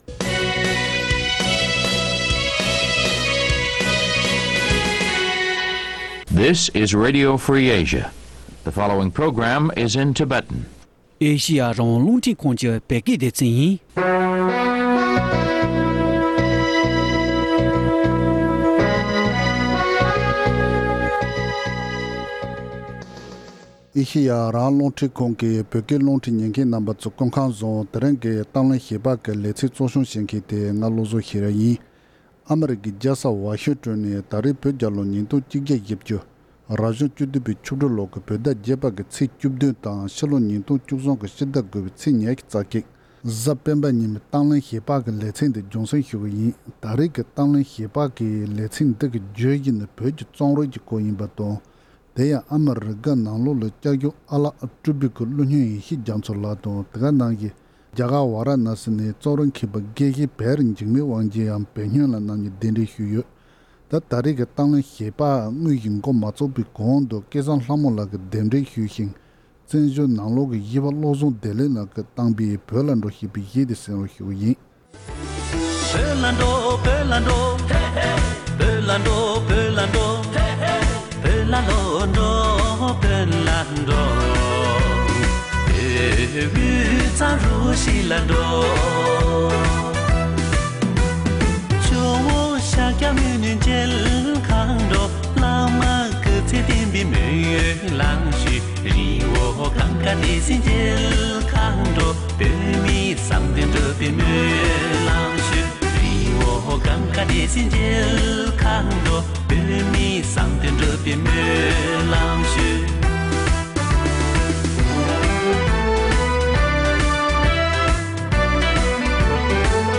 བཅར་འདྲི།